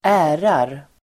Uttal: [²'ä:rar]